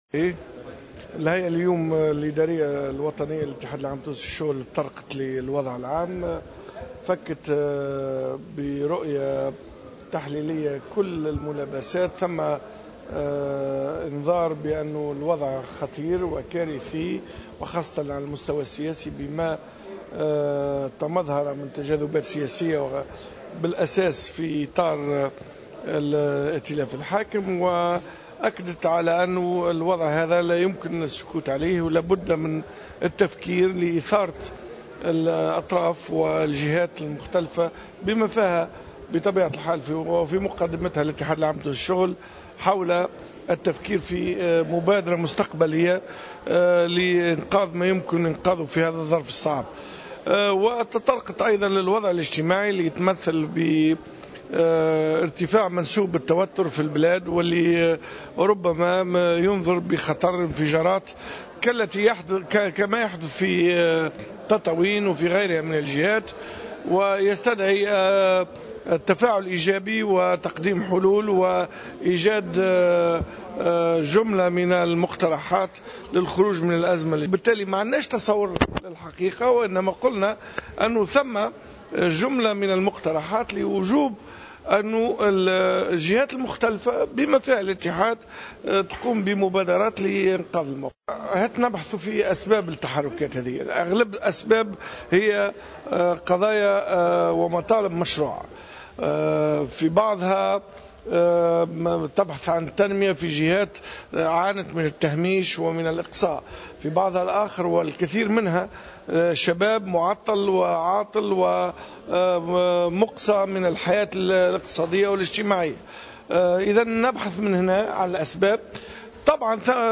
على هامش انعقاد الهيئة الادارية الوطنية للمنظمة الشغيلة
تصريح